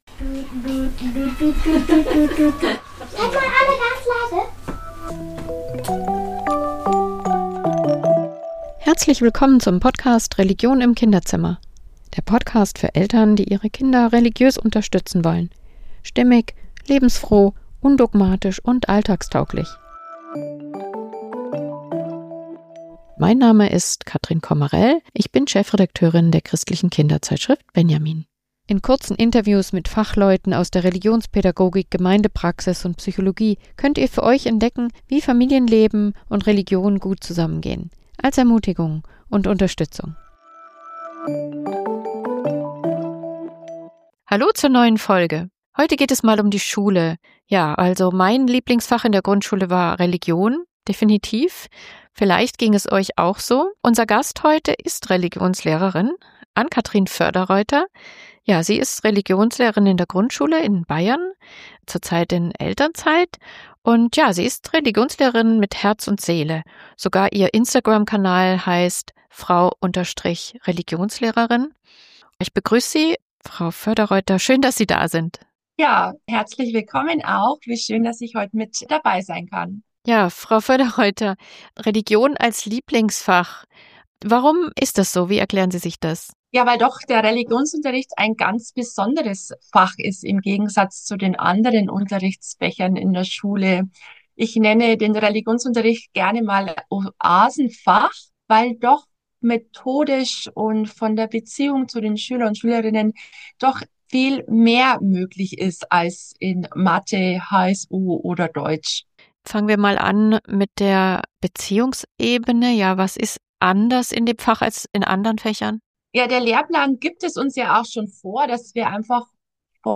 Warum Noten dabei nicht das Maß aller Dinge sind und welche Rolle ihre authentische Glaubensvermittlung spielt, erzählt sie im Gespräch. Diese Folge ist ein Plädoyer für den Religionsunterricht als Schulfach, das gesellschaftlich wichtige Werte vermittelt und Mut macht, sich mit sich selbst, dem Glauben und der Welt auseinanderzusetzen.